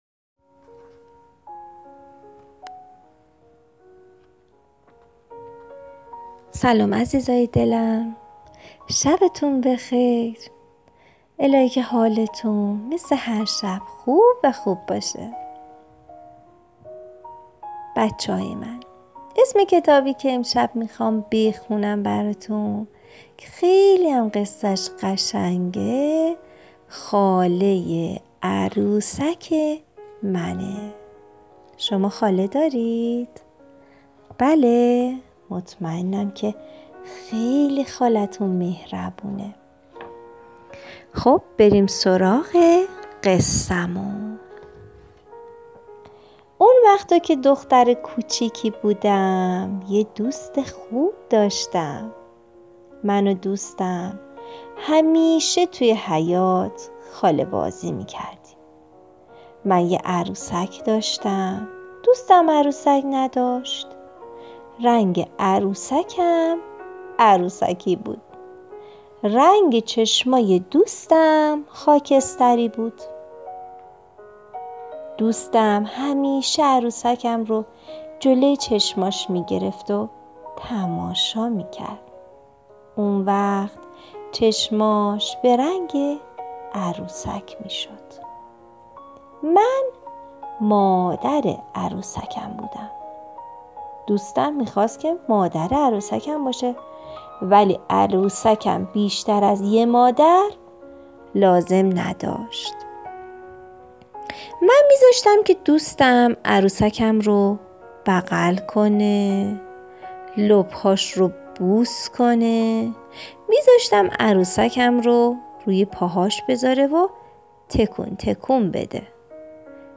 قصه صوتی کودکان دیدگاه شما 411 بازدید